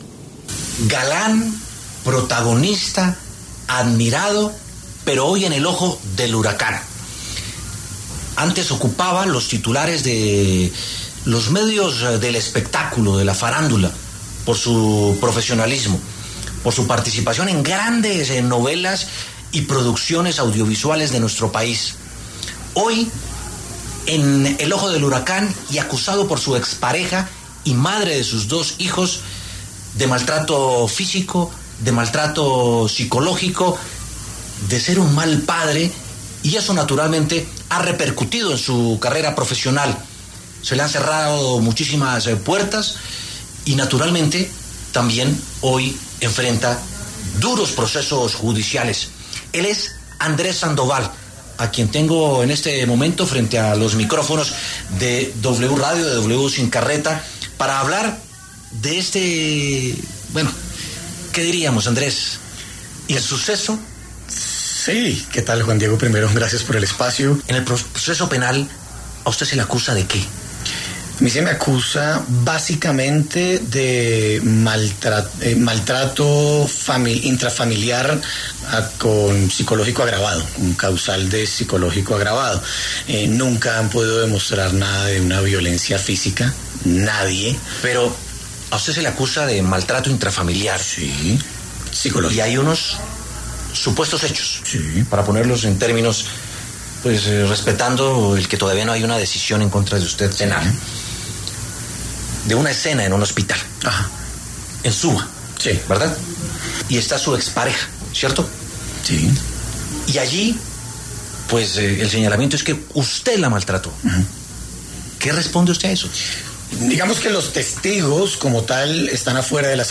El reconocido actor Andrés Sandoval, quien es señalado por su expareja por maltrato y violencia intrafamiliar, pasó por los micrófonos de W Sin Carreta y se refirió a dicho proceso judicial que aún permanece en etapa de recolección de pruebas.